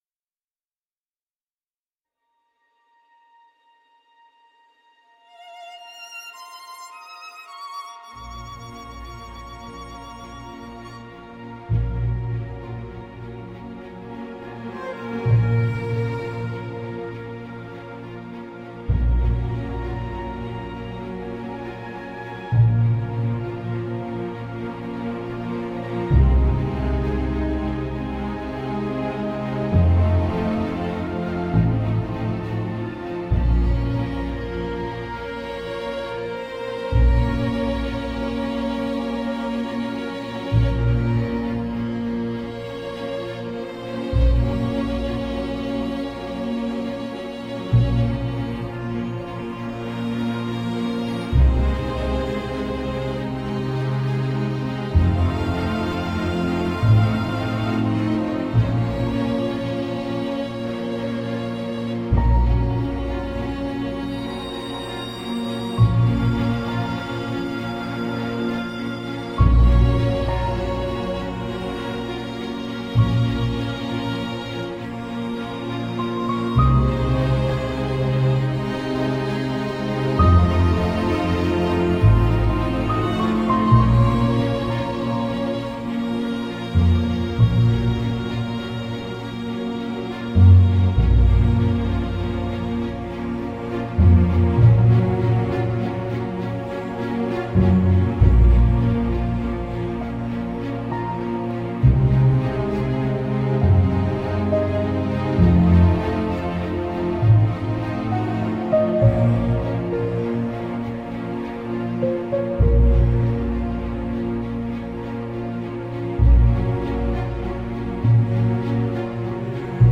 Грустная музыка для видео без слов